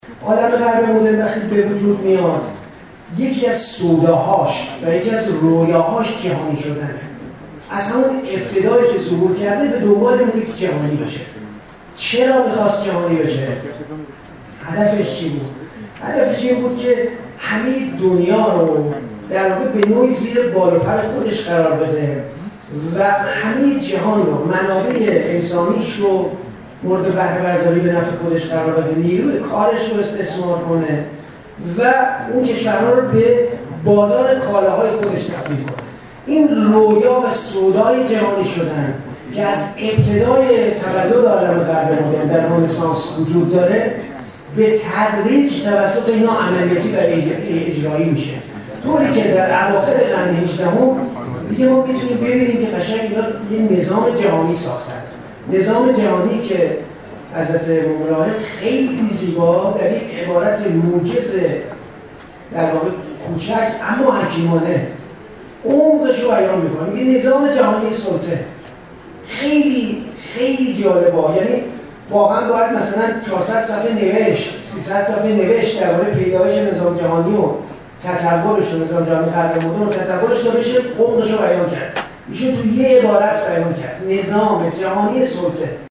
در نشست تبیین بیانیه گام دوم انقلاب اسلامی که در سالن همایش های مؤسسه آموزشی و پژوهشی امام خمینی(ره) برگزار شد